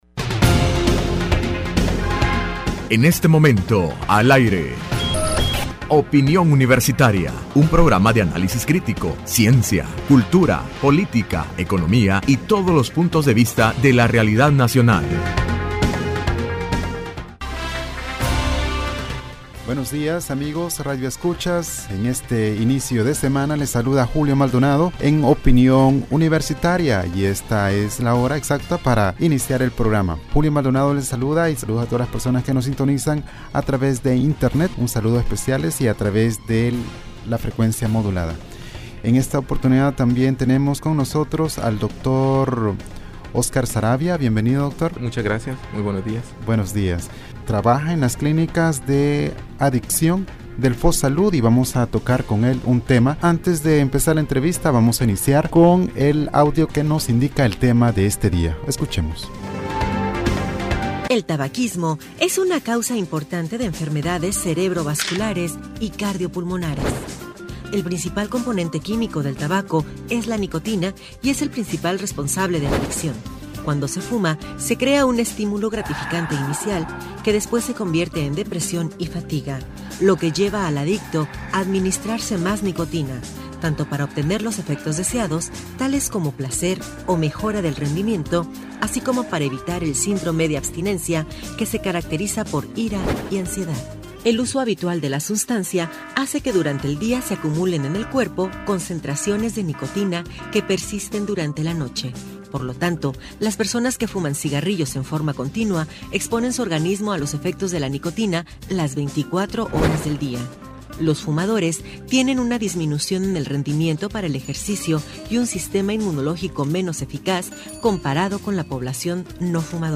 Entrevista Opinión Universitaria (31 de Mayo 2016): El tabaquismo, consecuencias contra la salud del humano.